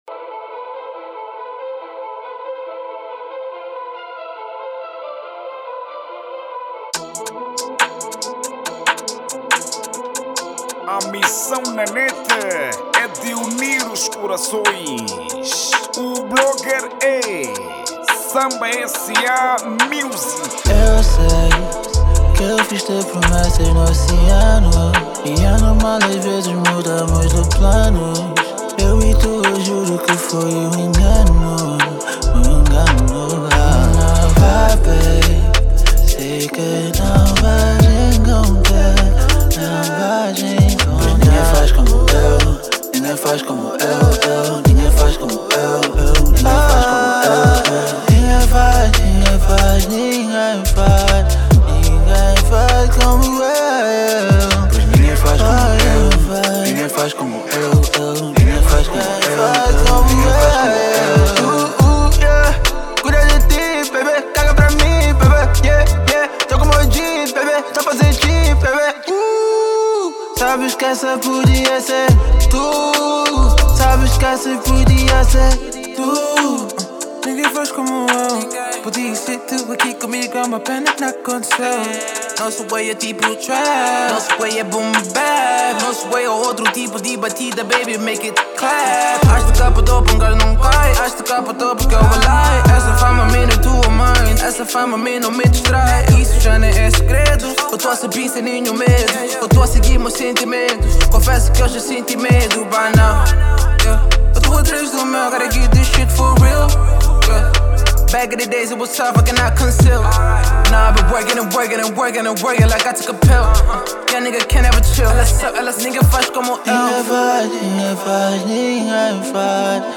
| RnB